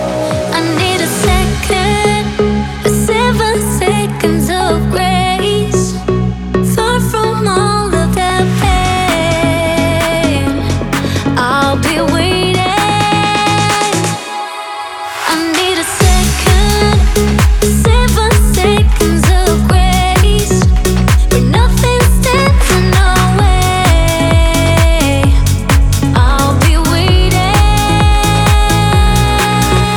House Dance
Жанр: Танцевальные / Хаус